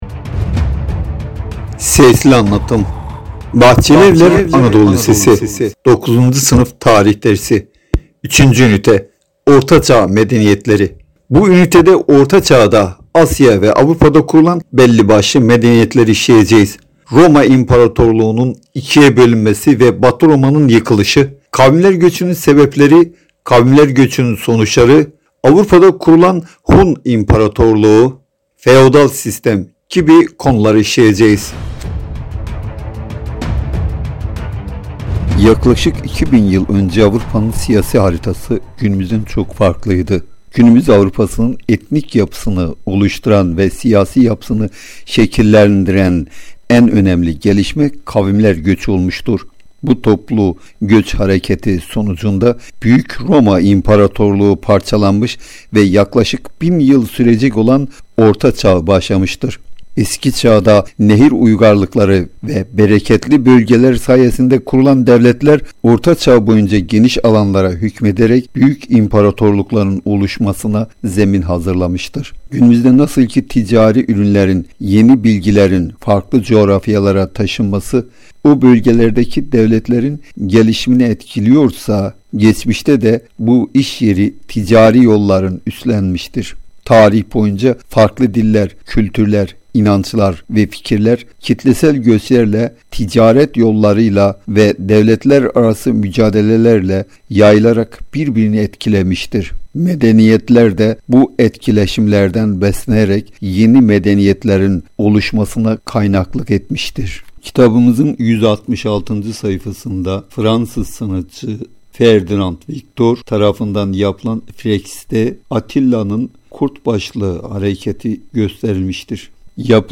Lise 9. S�n�f Tarih Dersi 3. �nite Sesli Anlat�m